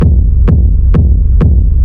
Sub kick Free sound effects and audio clips
• Techno Sub Kick Black 2.wav
Techno_Sub_Kick_Black_2__she.wav